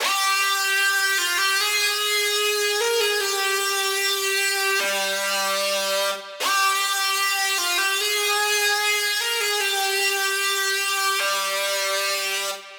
150BPM Lead 10 Fmin.wav